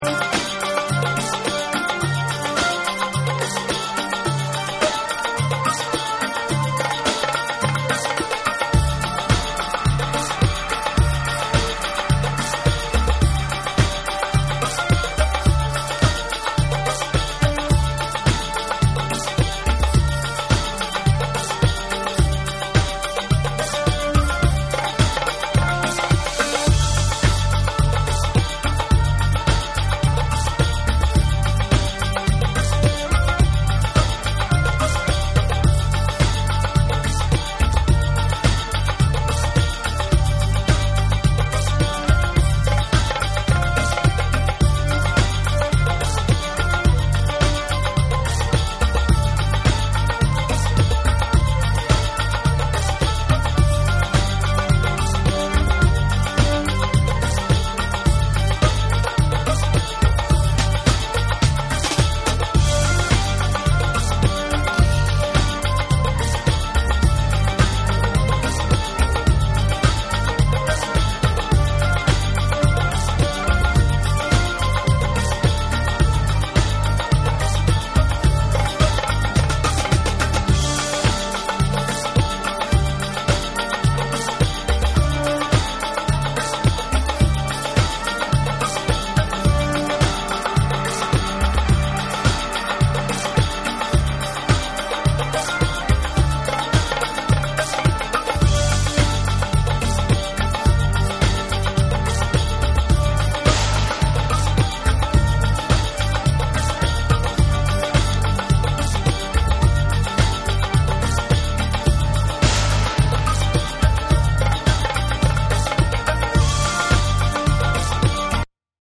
民族調のヴォイス・サンプルなどを使ったトライバル・ジャングル・ナンバー
重厚で伸し掛るようなエスニック・ニュールーツ・ダブ
REGGAE & DUB / ROOTS & CULTURE